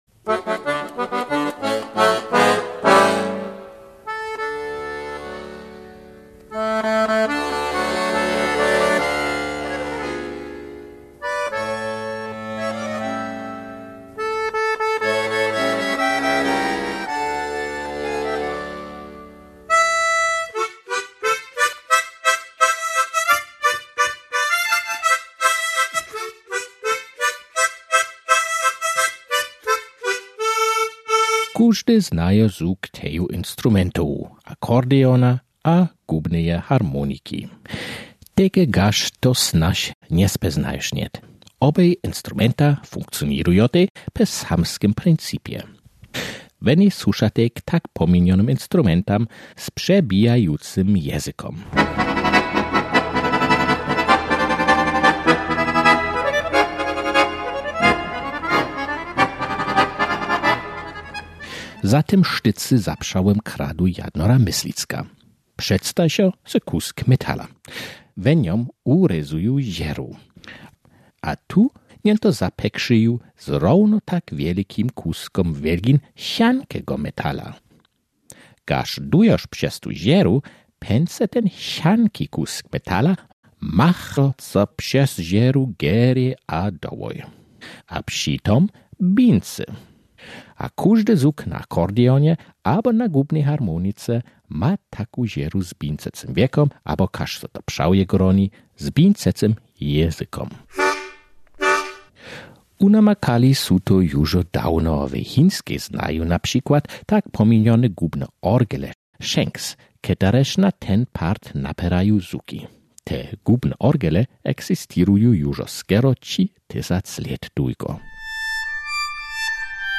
Pśedstajamy muzikowe instrumenty: kontrabas.